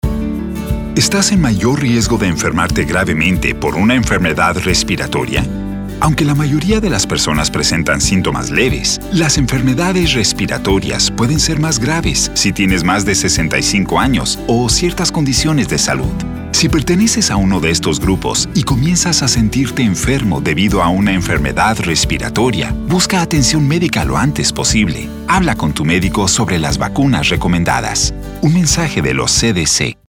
• Use these radio PSAs and scripts to share information about who might be at higher risk for serious illness from respiratory illnesses and how they can protect themselves.